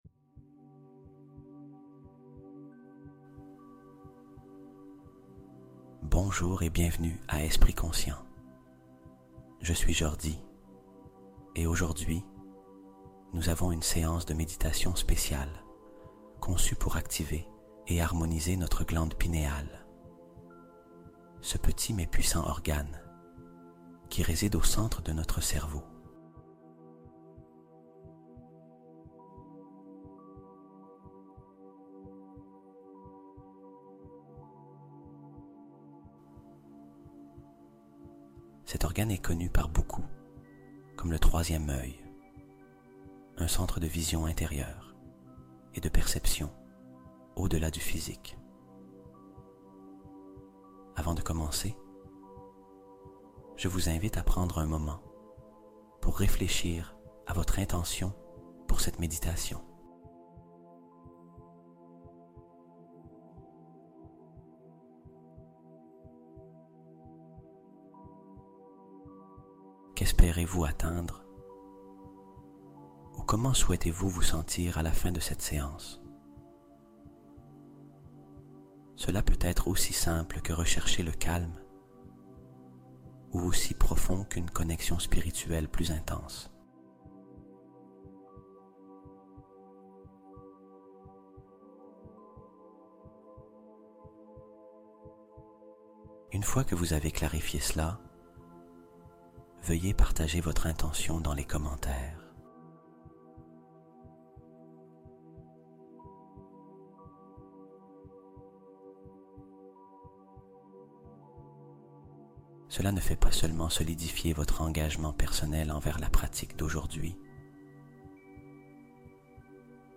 Clarté du Regard : Activation de la perception intuitive par le son 741 Hz